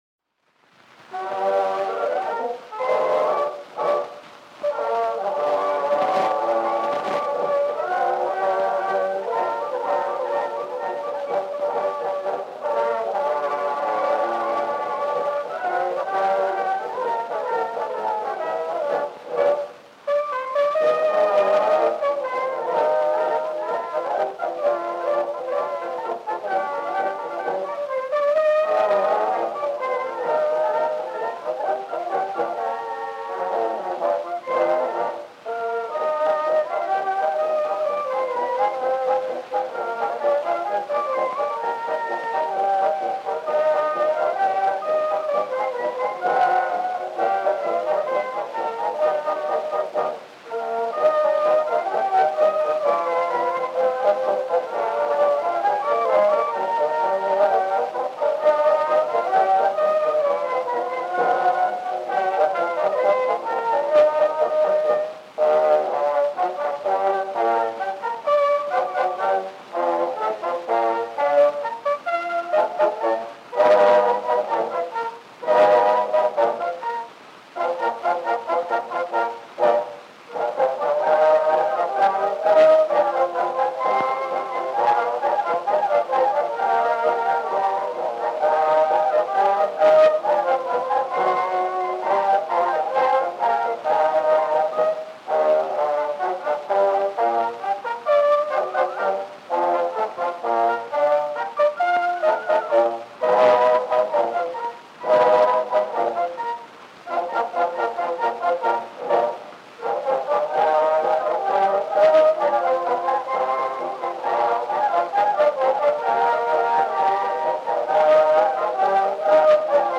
March